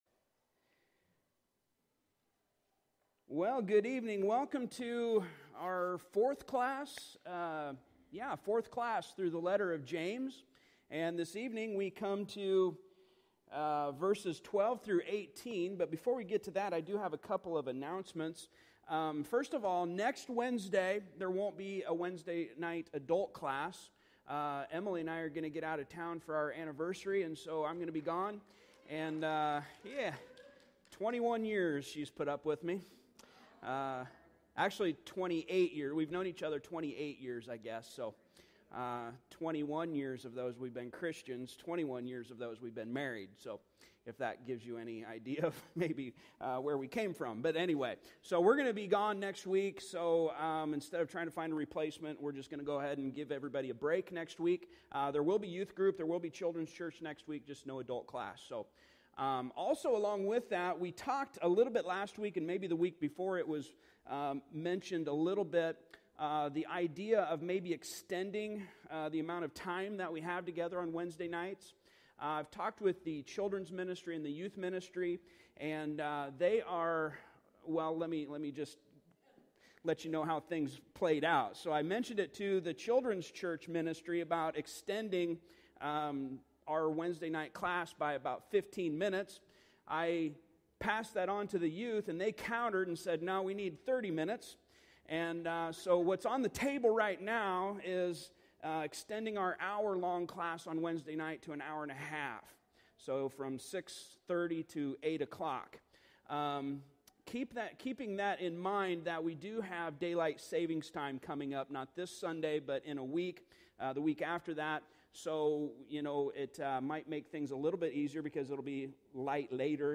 Passage: James 1:12-18 Service Type: Midweek Service